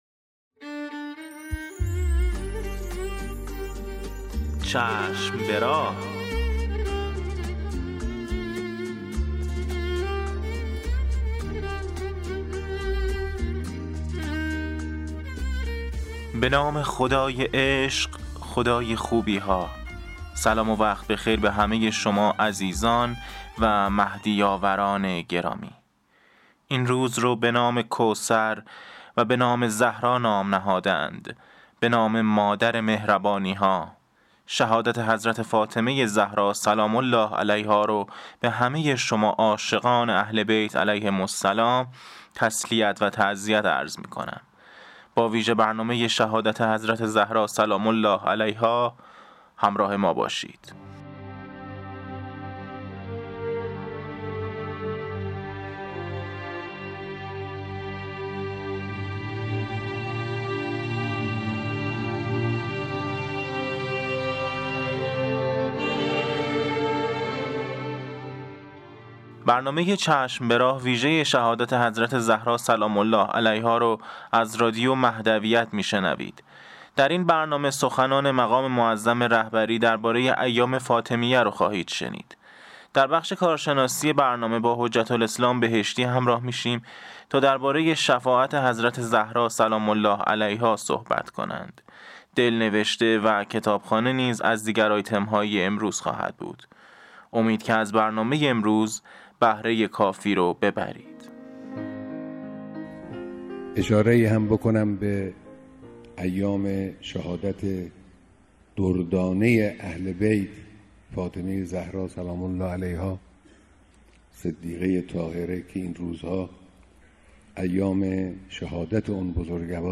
شعرخوانی
نوحه فاطمی